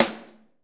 SD 2H.wav